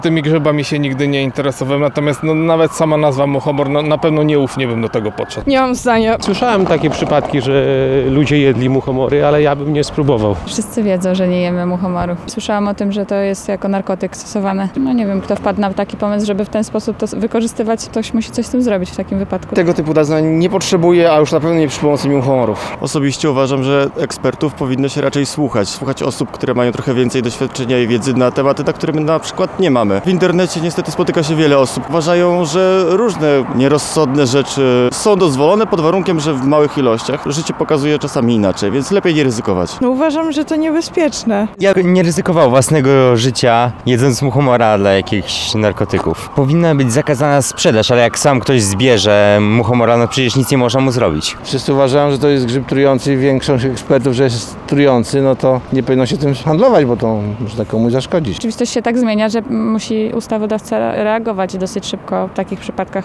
Trujące wieści – sonda
Zapytałam Warszawiaków co myślą w tej sprawie: